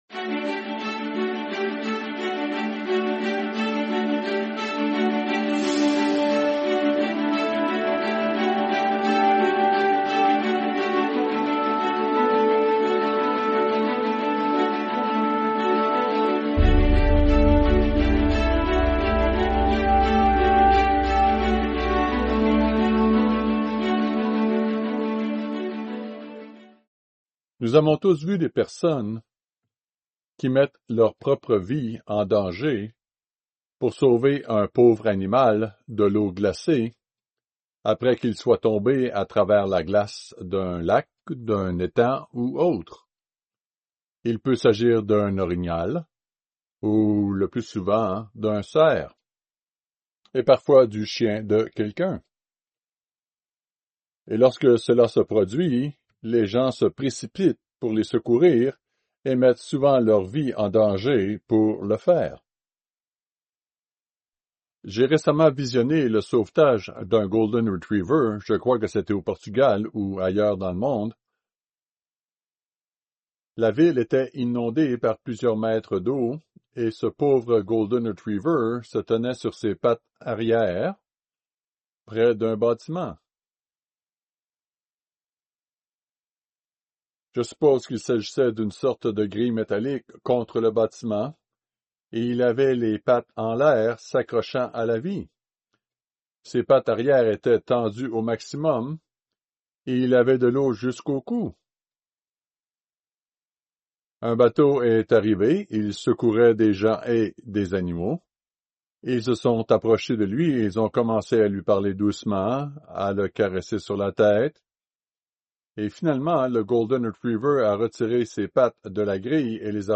Ce sermon parle de notre besoin de nous traiter les uns les autres avec courtoisie et bonté. Le mode de vie de Dieu est celui de la sollicitude, de l’attention portée à l’autre.